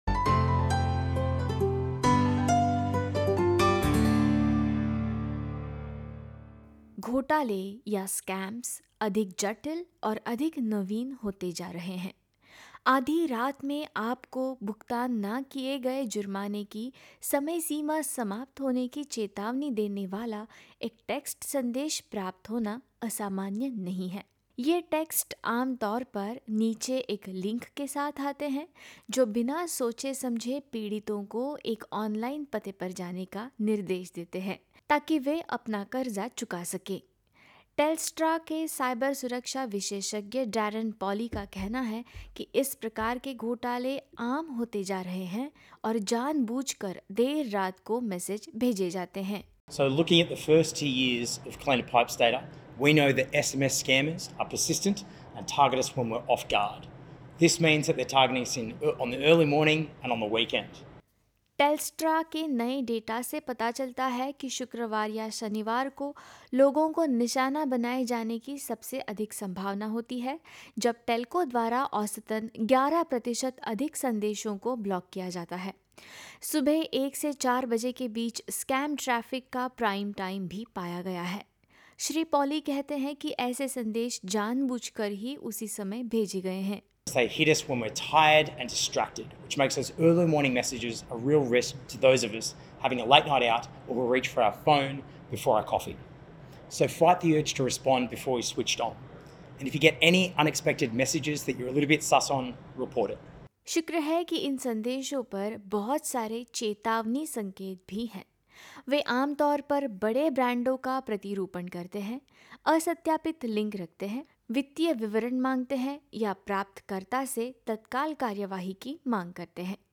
वे अपने संदेशों को ऑस्ट्रेलियाई रूप रंग देने के लिए आर्टिफीसियल इंटेलिजेंस का भी प्रयोग कर रहें हैं। इसी विषय पर प्रस्तुत है एक रिपोर्ट।